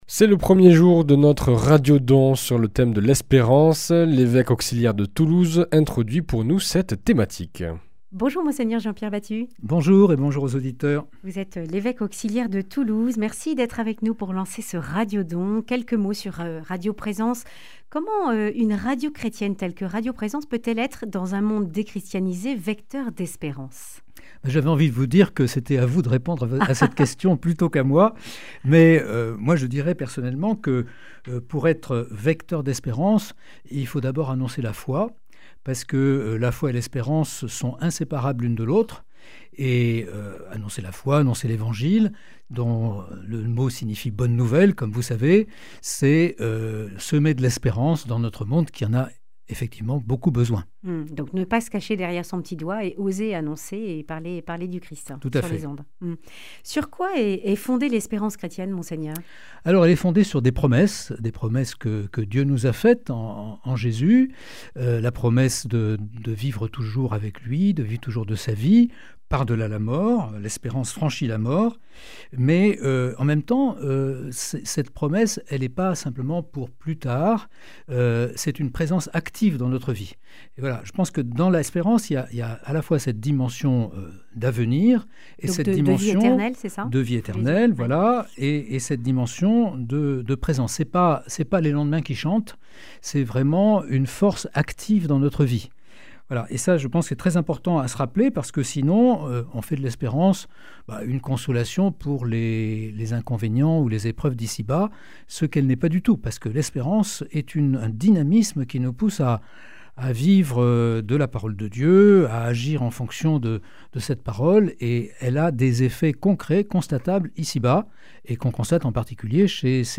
Quelles sont les différences entre l’espoir et l’espérance ? Monseigneur Jean-Pierre Batut, évêque auxiliaire de Toulouse.
Le grand entretien